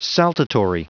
Prononciation du mot saltatory en anglais (fichier audio)
Prononciation du mot : saltatory